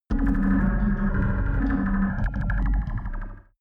Gemafreie Sounds: Digital